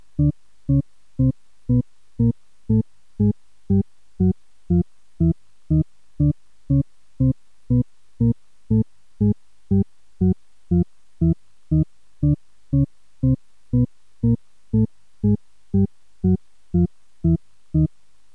The demostration uses a cyclic set of complex tones, each composed of 10 partials separated by octave intervals.
Click here to listen to the descending Shepard scale